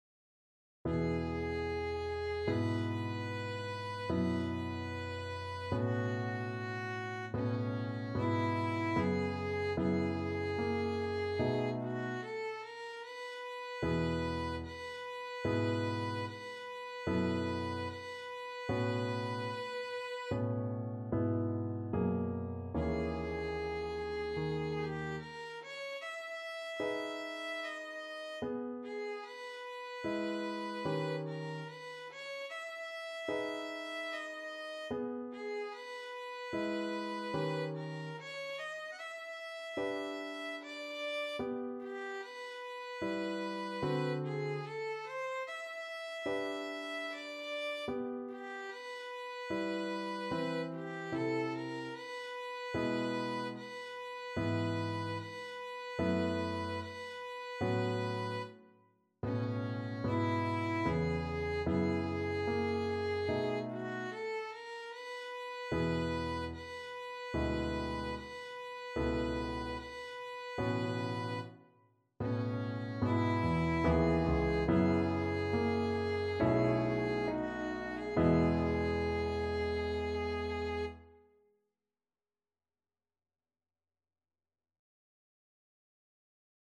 Violin
E major (Sounding Pitch) (View more E major Music for Violin )
4/4 (View more 4/4 Music)
Andante con moto =74 (View more music marked Andante con moto)
Classical (View more Classical Violin Music)